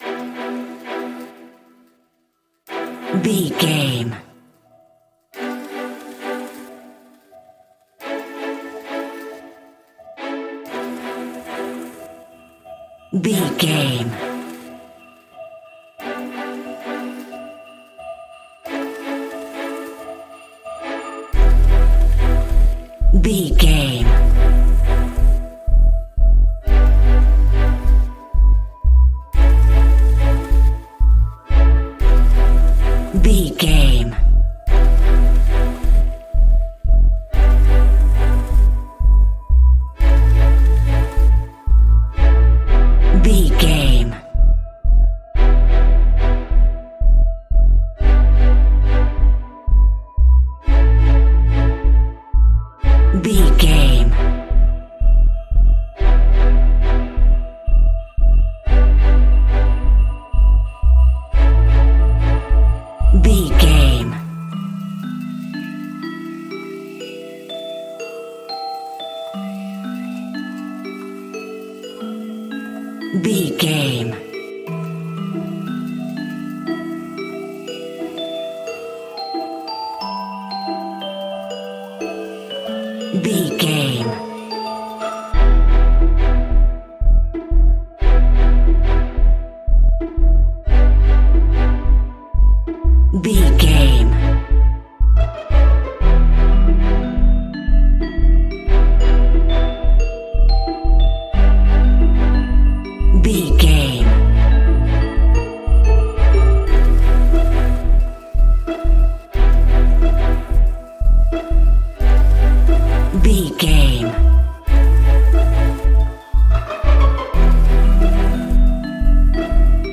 Aeolian/Minor
Slow
eerie
groovy
dark
synthesiser
percussion
sleigh bells
strings